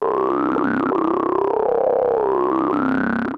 TALKING OSC 1.wav